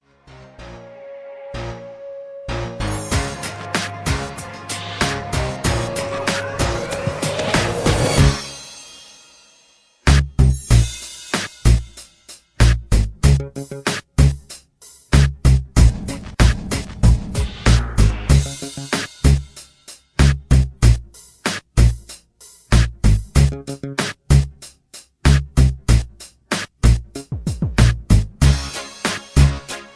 soul music